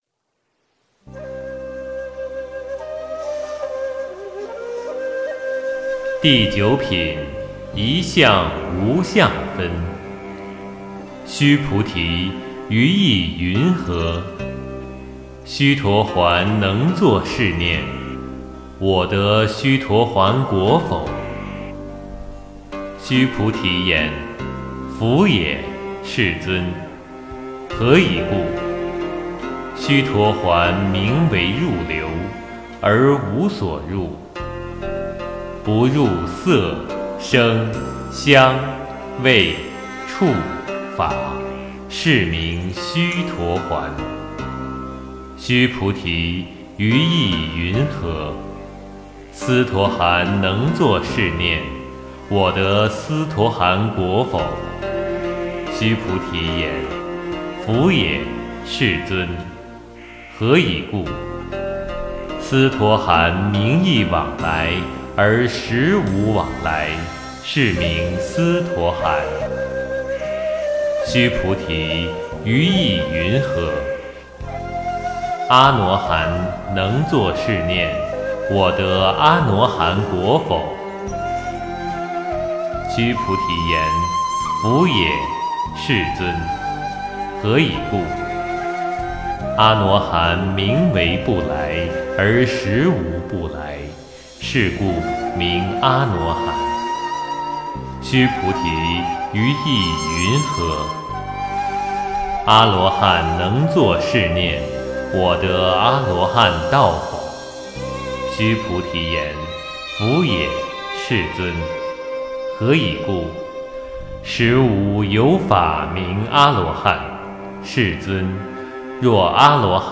金刚经：第九品和第十品 - 诵经 - 云佛论坛